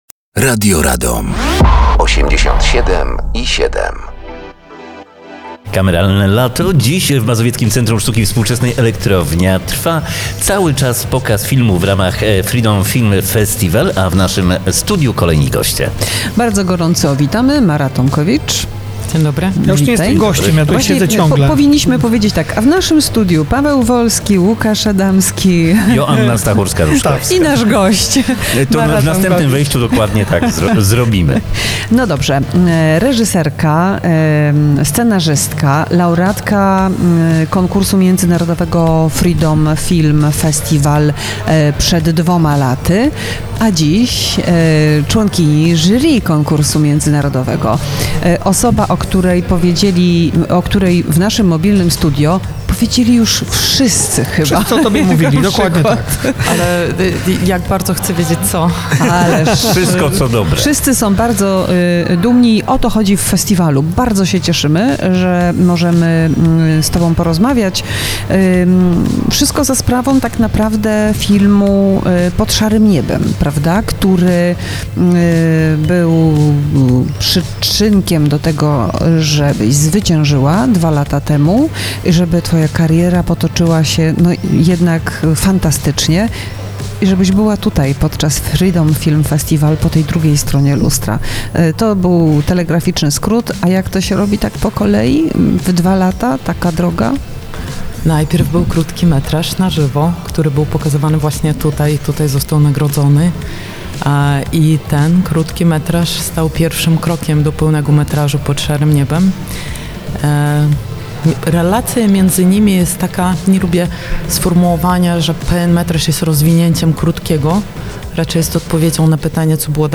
Kameralne Lato